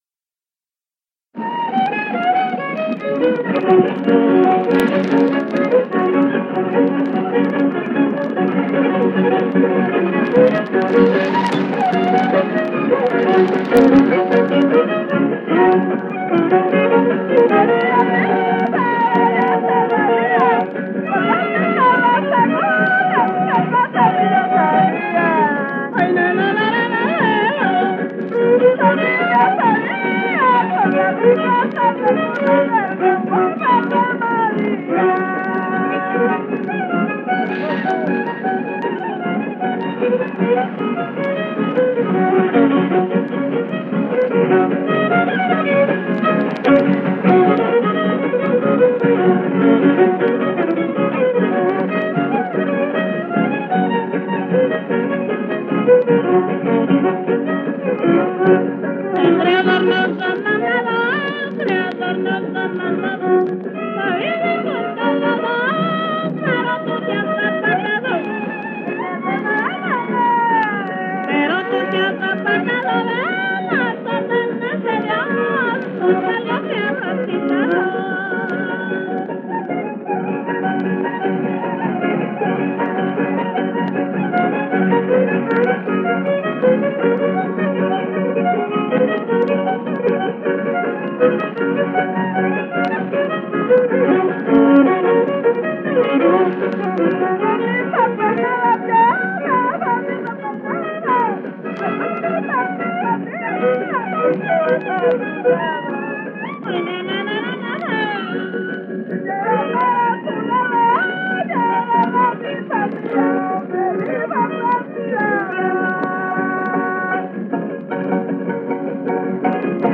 Keywords: huapangos poblanos
violín y voz
voz y jarana
voz, guitarra sexta y guitarra quinta
trovador.